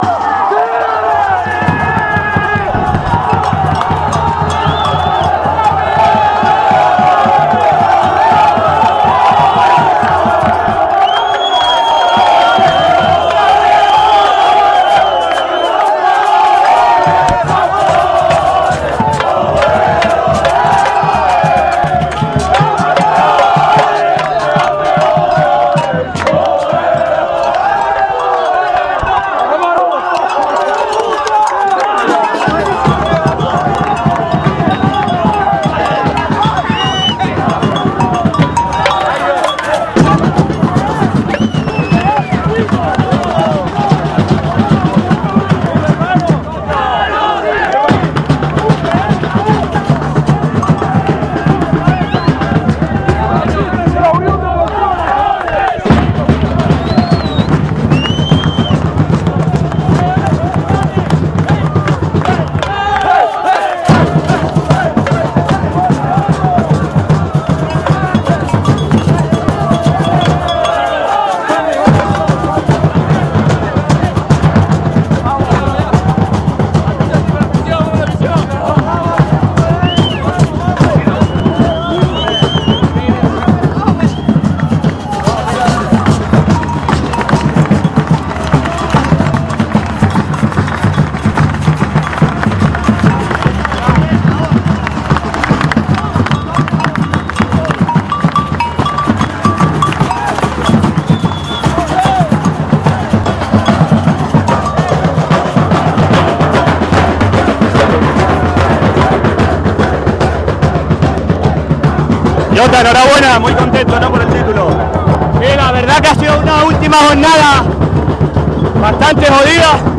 Festejos.mp3